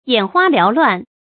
眼花繚亂 注音： ㄧㄢˇ ㄏㄨㄚ ㄌㄧㄠˊ ㄌㄨㄢˋ 讀音讀法： 意思解釋： 繚亂：紛亂。眼睛看見復雜紛繁的事物而發花、迷亂。